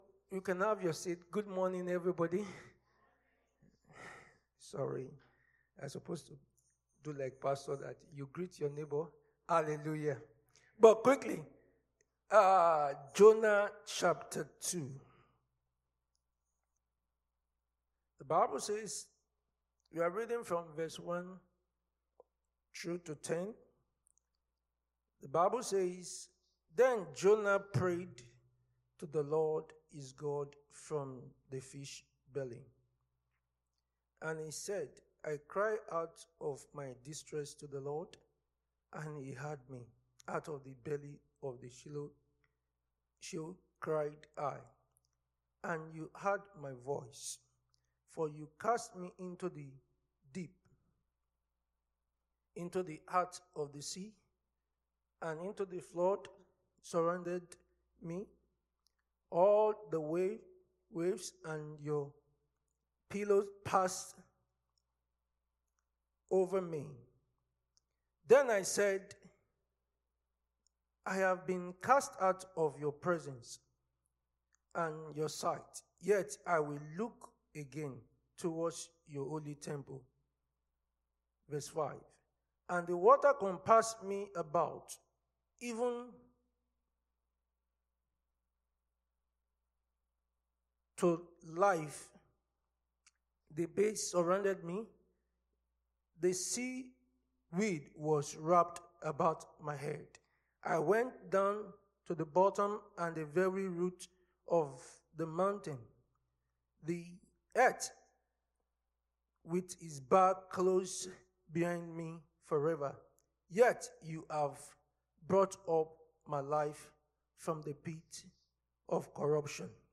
Sunday Sermon: The Force Of Thanksgiving
Service Type: Sunday Church Service